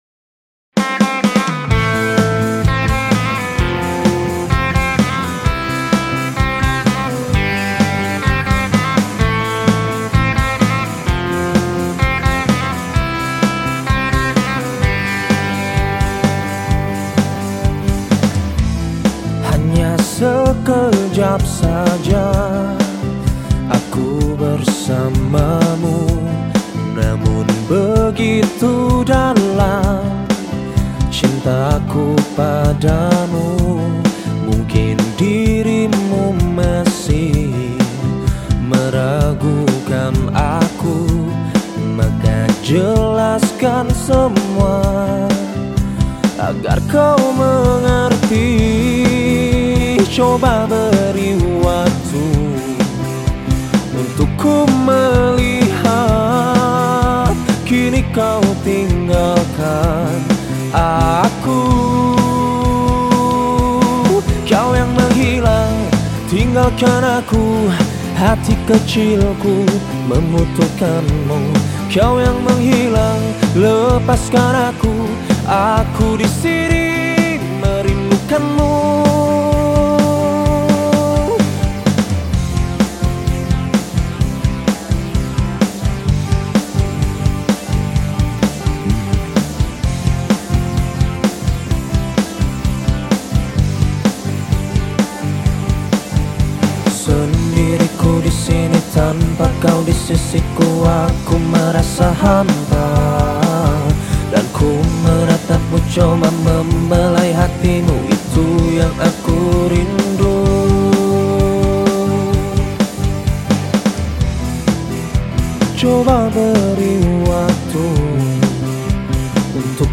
Genre Musik                             : Pop